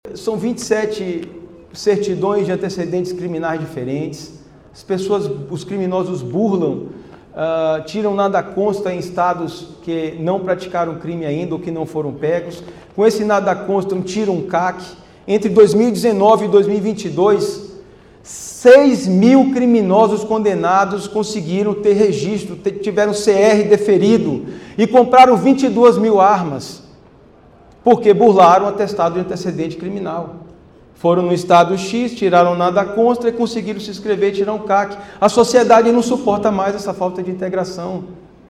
Manoel Carlos de Almeida, Secretário-executivo do MJSP, explica como criminosos aproveitam a falta de integração interestadual de dados para ter acesso a armas — Ministério da Justiça e Segurança Pública